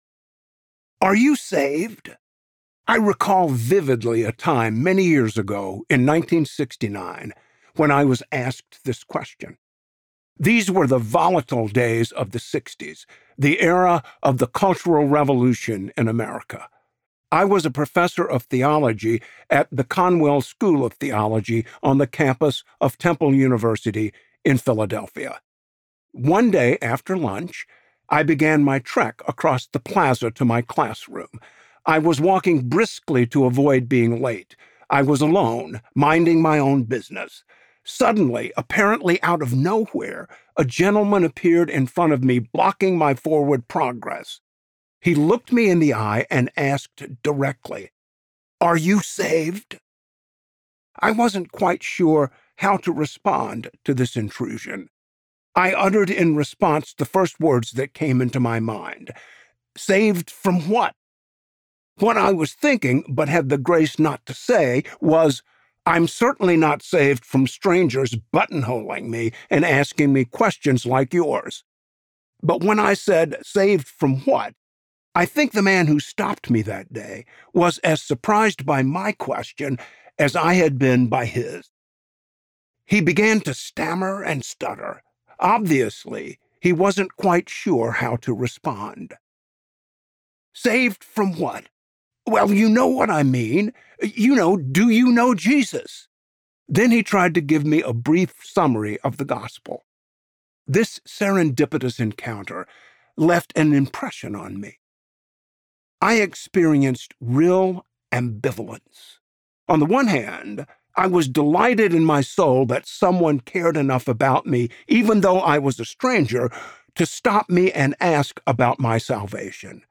The Great Rescue: R.C. Sproul - Audiobook Download, Book | Ligonier Ministries Store